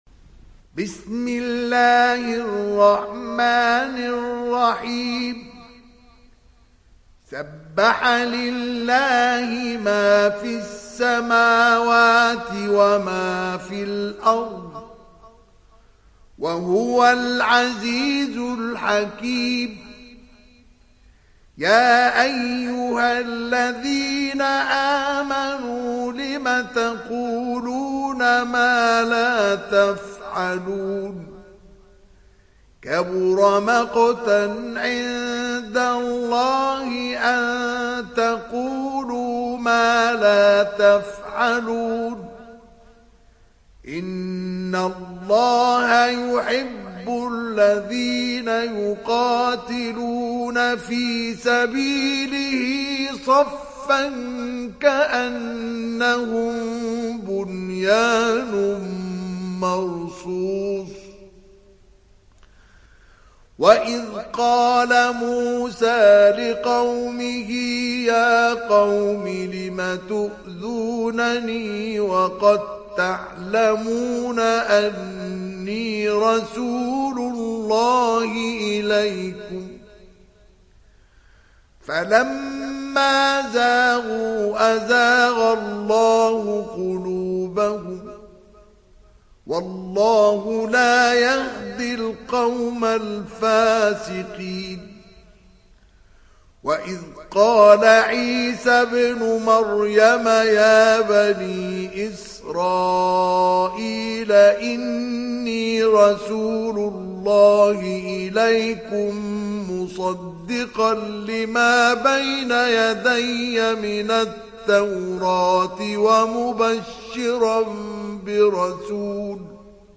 Sourate As Saf Télécharger mp3 Mustafa Ismail Riwayat Hafs an Assim, Téléchargez le Coran et écoutez les liens directs complets mp3